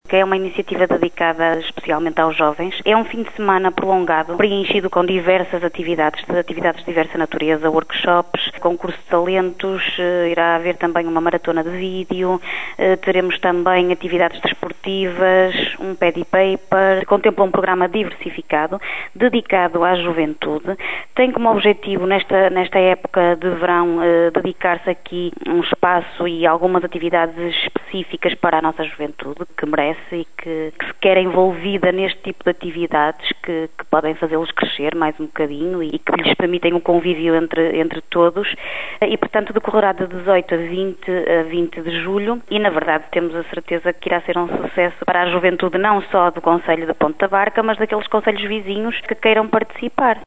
A vereadora responsável pelo pelouro da Cultura na Câmara de Ponte da Barca, Sílvia Torres, revela os pormenores.